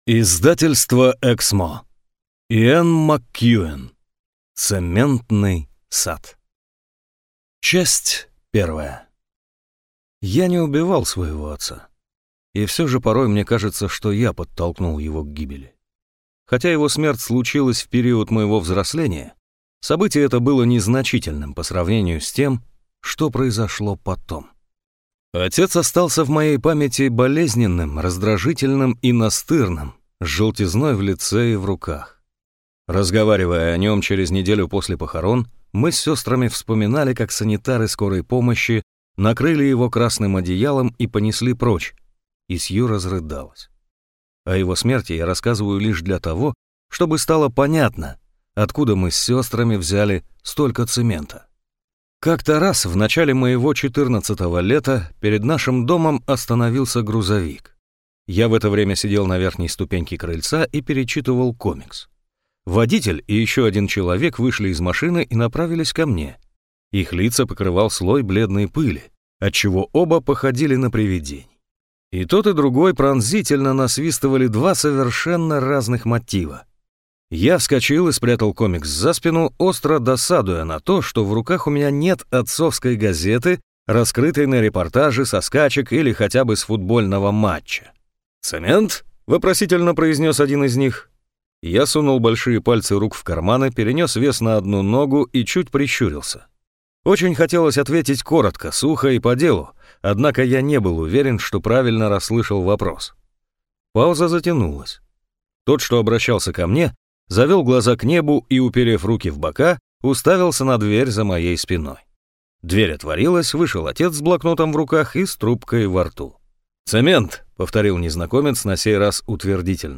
Аудиокнига Цементный сад | Библиотека аудиокниг